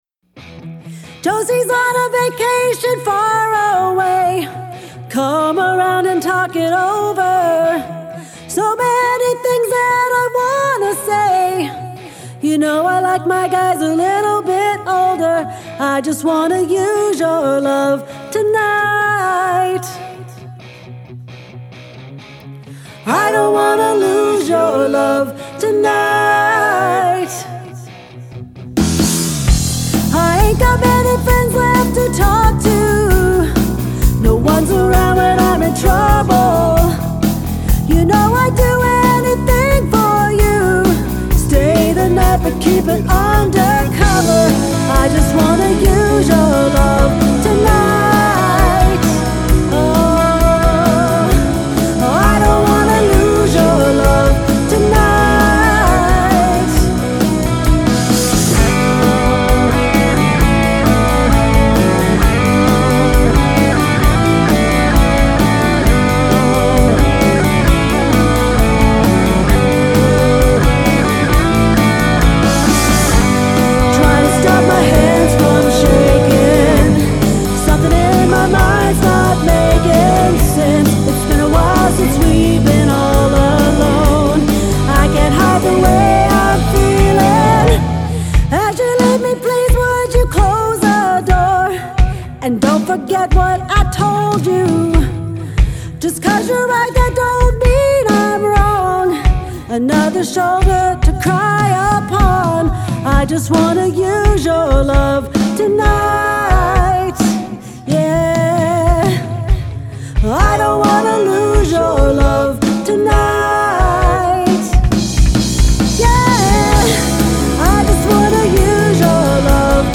dance/electronic
Great vocal work makes this a tough song.
Rock & Roll
Pop